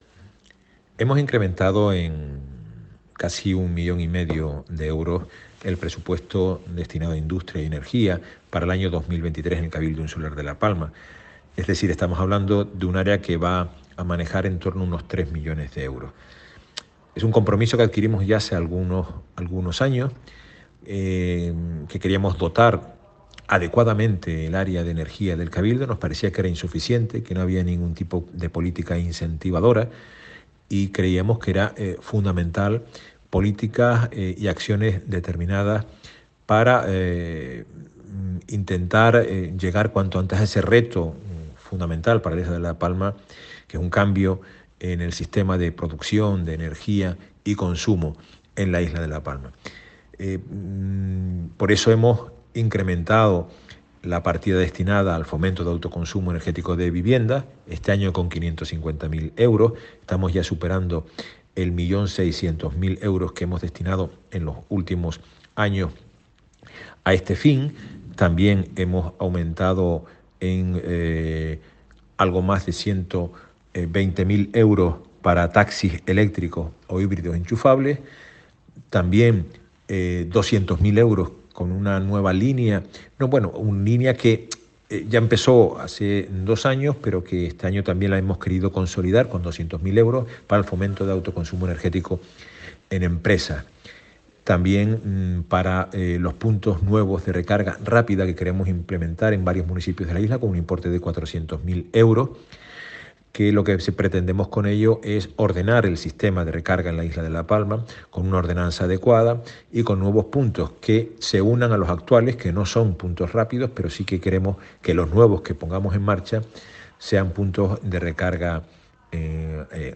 Declaraciones audio Calros Cabrera presupuesto Energía.mp3